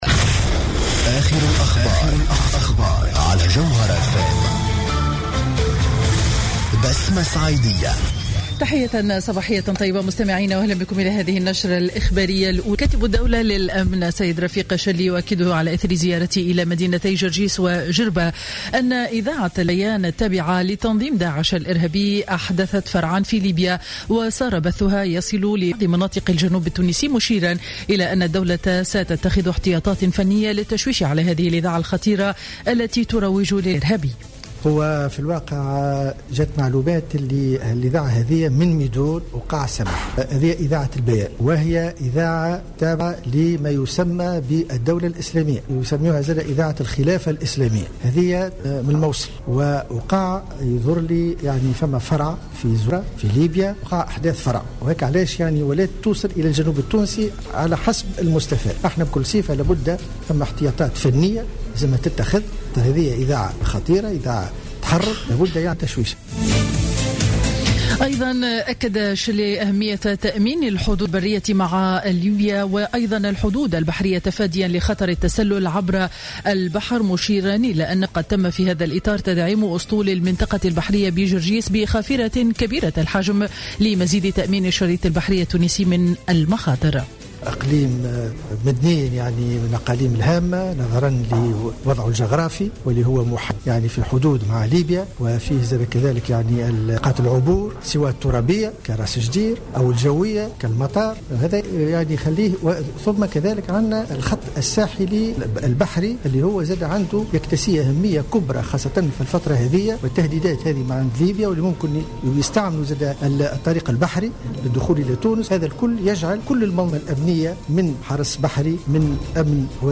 نشرة أخبار السابعة صباحا ليوم الإربعاء 9 سبتمبر 2015